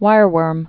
(wīrwûrm)